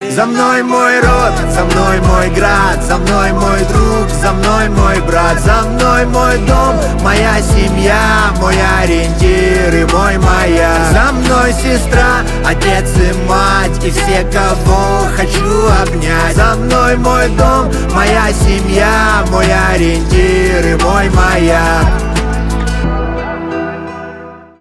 русский рэп
гитара